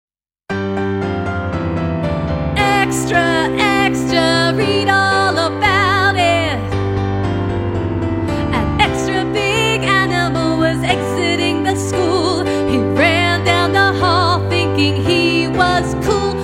Step and stop for slower parts.